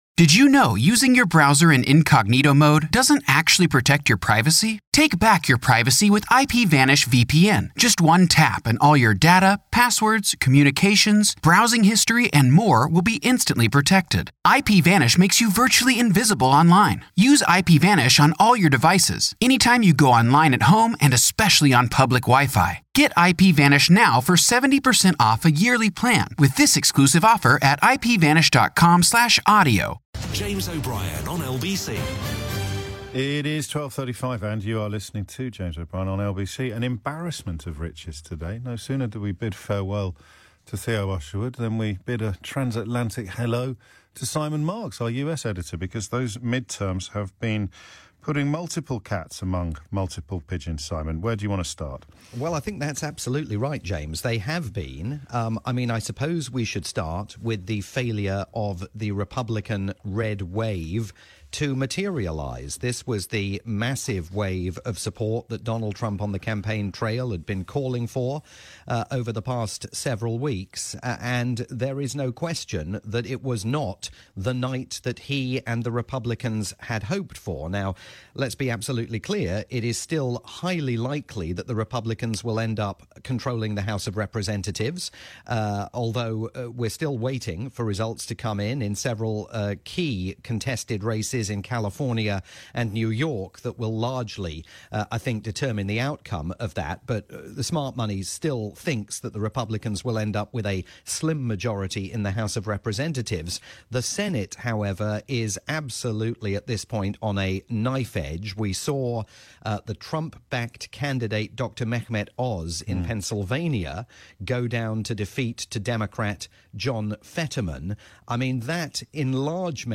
live update for James O'Brien's morning programme on the UK's LBC.